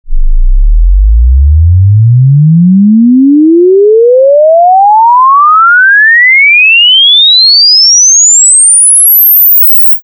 Logarithmic frequency sweep from 20 Hz to 20 KHz (1 oct. / sec.) 00' 10" sweep004
sweep004.mp3